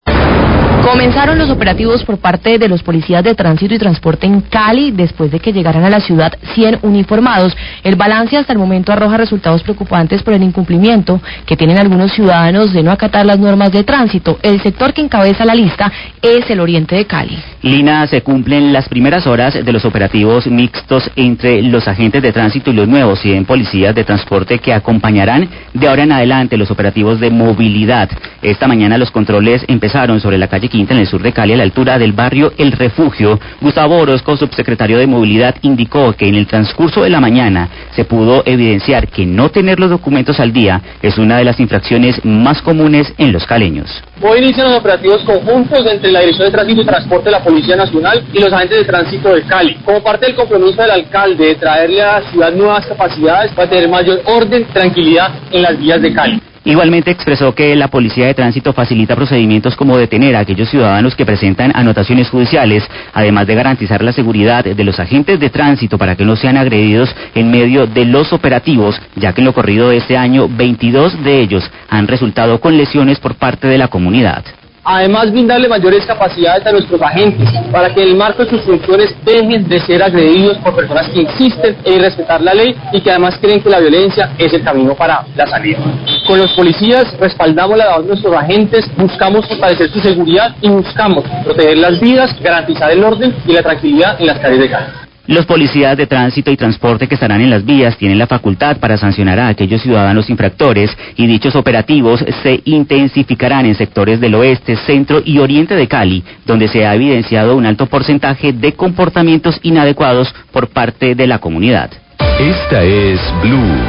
Radio
Declaraciones del Subsecretario de Movilidad de Cali, Gustavo Orozco.